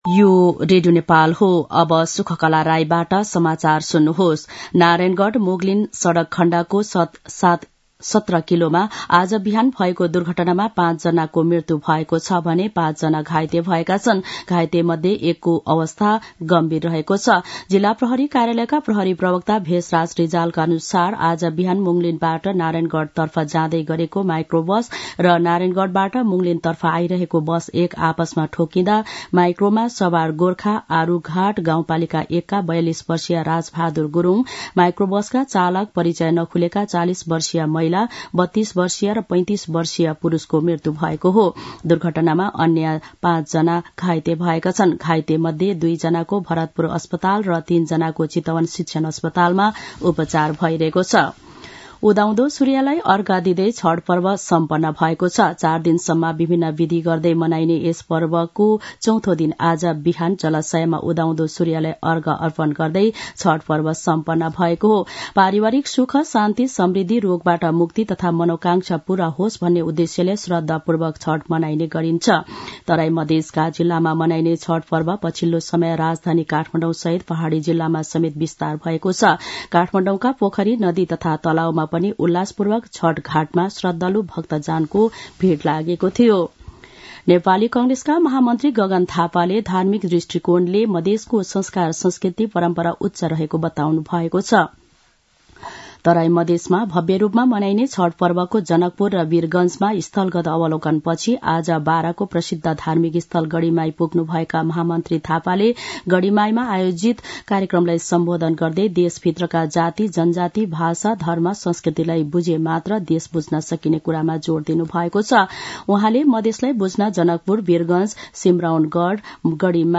An online outlet of Nepal's national radio broadcaster
मध्यान्ह १२ बजेको नेपाली समाचार : २४ कार्तिक , २०८१